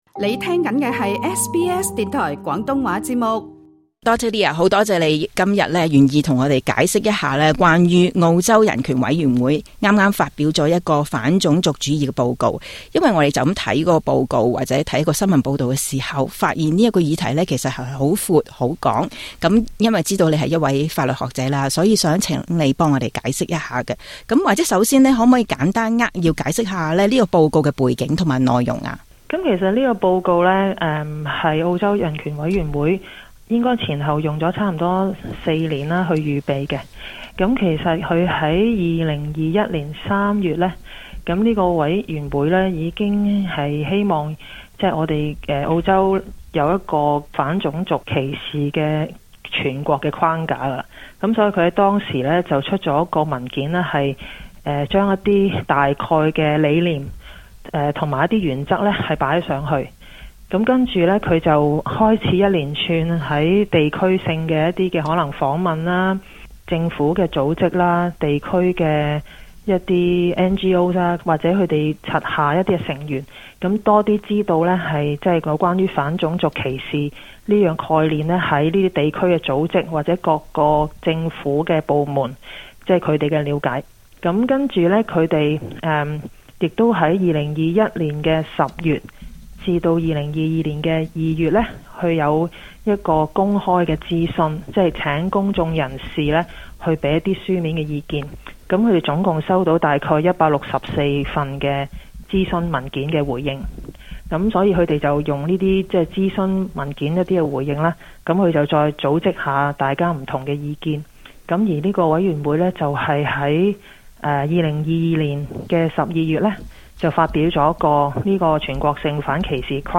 【時事專訪】